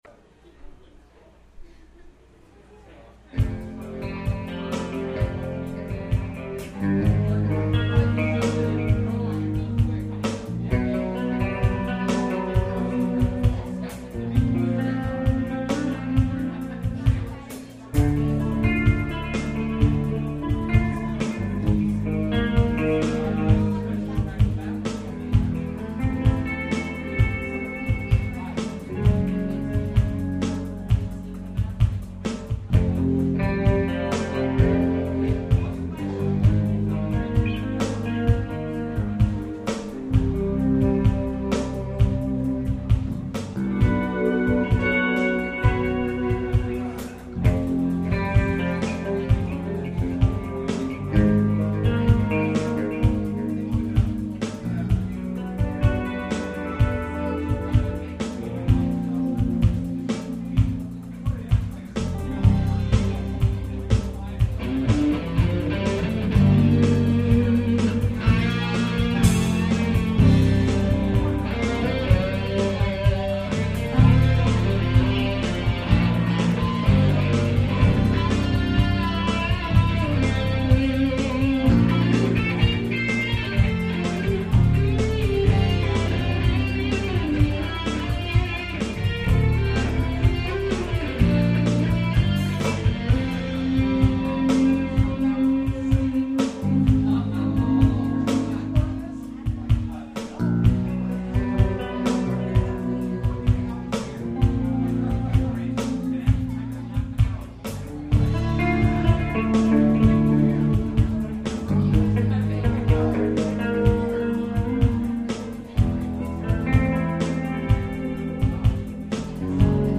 "Live" at the Metaphor Cafe in Escondido, CA
Guitar
Bass
Electronic Drums